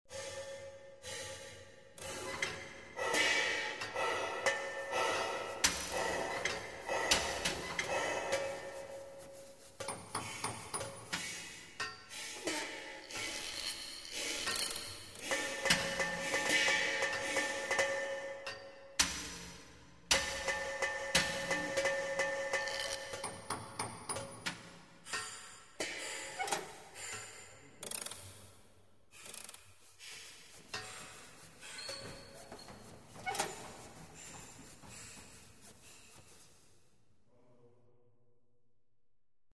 На этой странице собраны звуки кузницы и работы с наковальней: ритмичные удары молота, звон металла, фоновый гул мастерской.
Звук работающей кузницы атмосферный hd